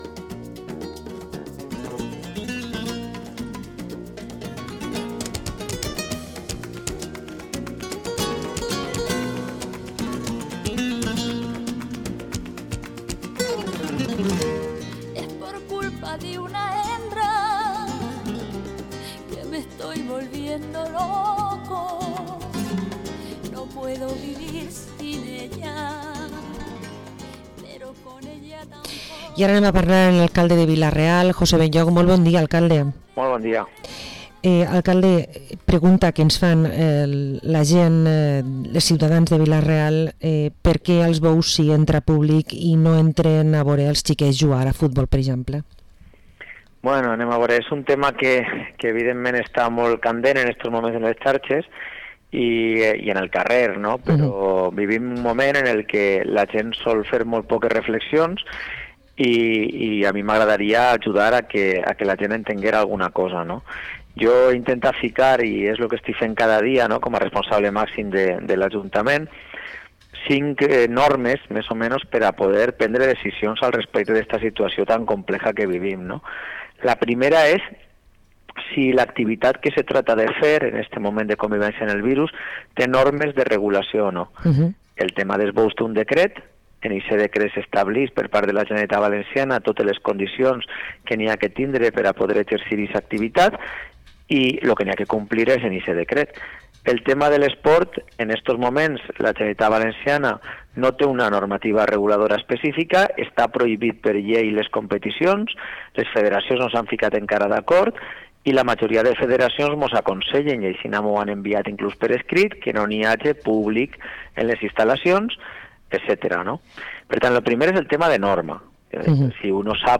Entrevista al alcalde de Vila-real, José Benlloch – Radio Vila-real 92.2 FM